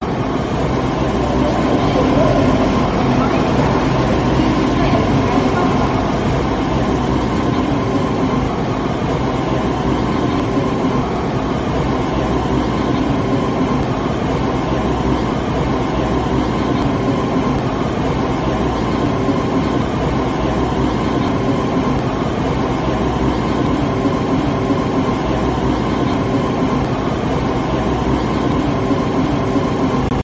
Tiếng Tàu Điện Metro Sài Gòn, Tp.HCM
Thể loại: Tiếng xe cộ
Description: Tiếng tàu điện tại ga Metro Sài Gòn, TP.HCM, là bản giao hưởng đô thị đầy sống động, hòa quyện giữa âm thanh rít nhẹ của tàu lướt trên đường ray, tiếng loa thông báo vang vọng và nhịp điệu hối hả của dòng người. Không gian ga rộn ràng với tiếng bước chân, tiếng nói cười, và âm vang cơ khí, tạo nên một bức tranh âm thanh đặc trưng của giao thông hiện đại.
tieng-tau-dien-metro-sai-gon-tp-hcm-www_tiengdong_com.mp3